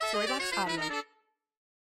Cartoon Accent Sound Effect
cartoon-accent-saxophone-section-descend_z154n_Vd.mp3